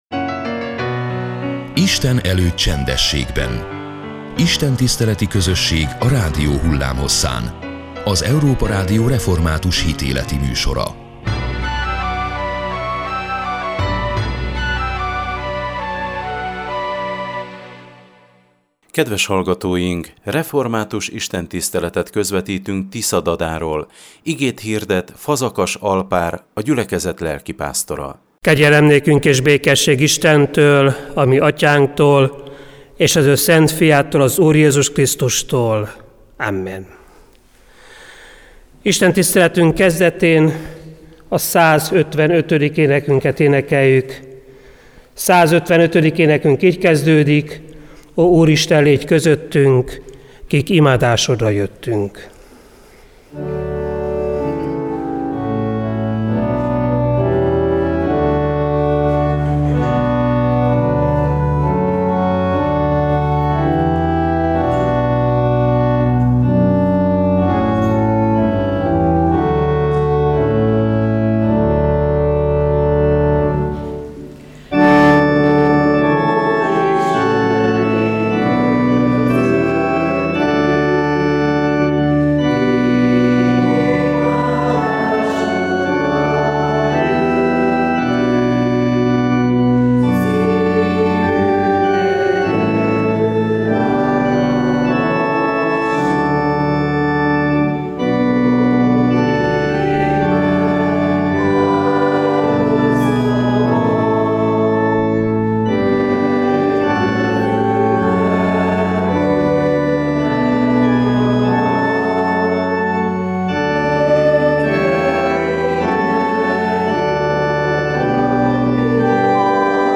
Református istentiszteletet közvetítettünk Tiszadadáról.